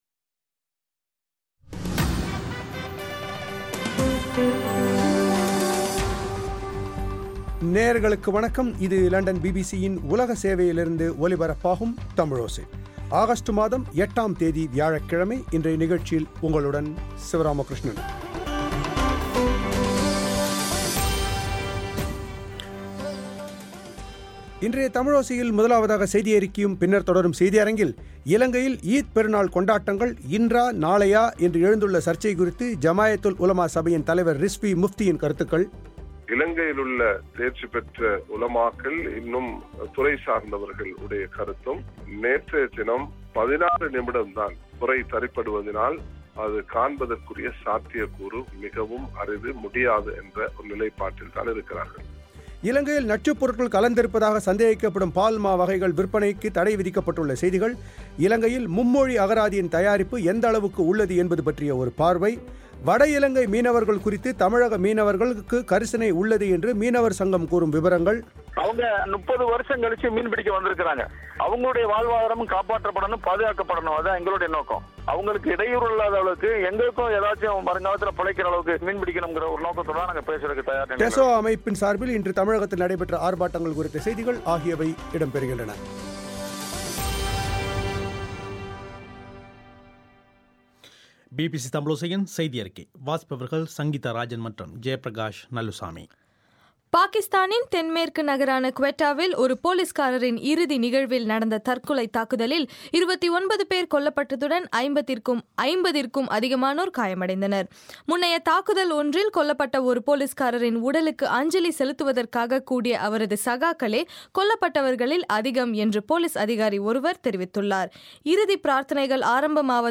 ஆகஸ்ட் 1 தமிழோசையின் உலகச் செய்திகள்